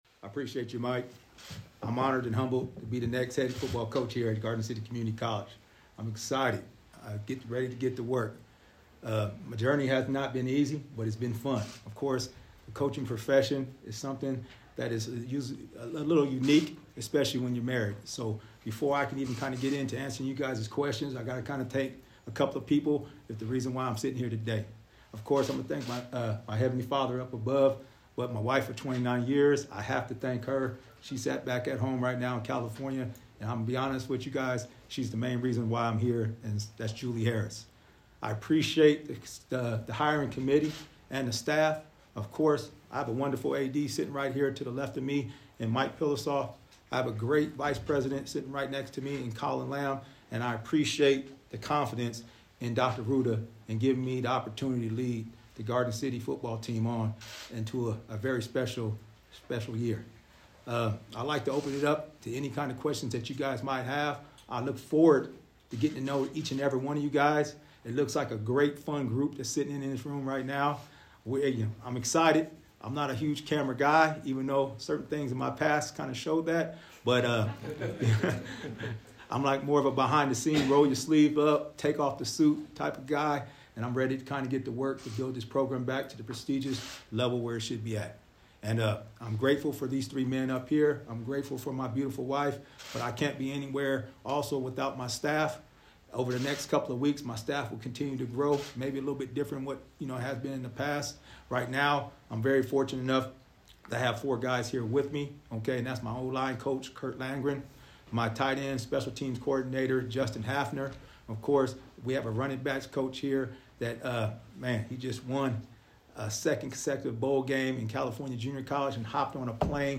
Opening statement